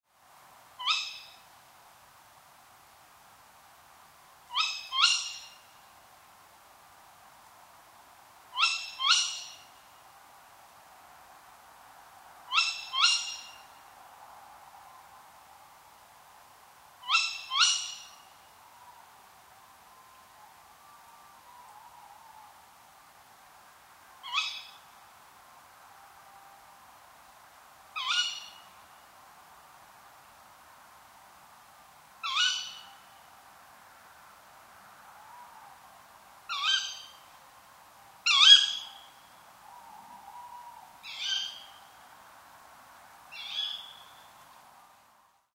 Cris-chouette-hulotte.mp3